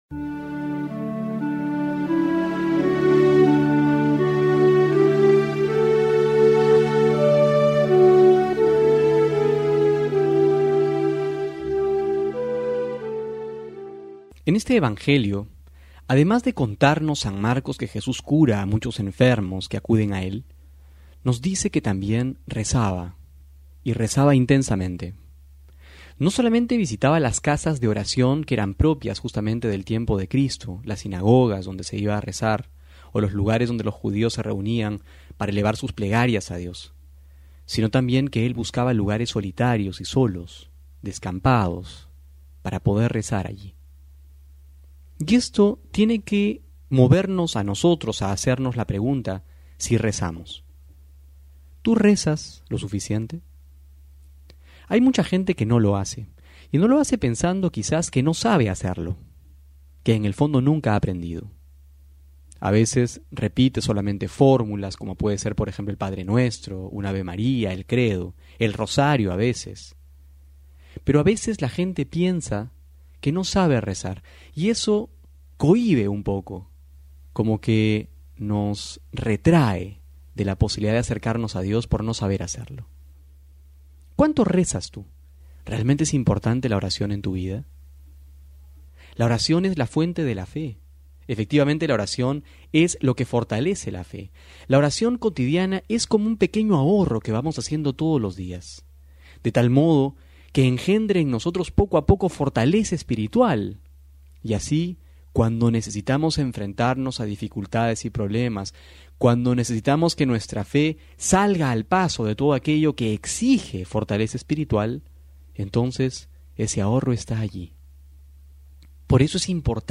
febrero05-12homilia.mp3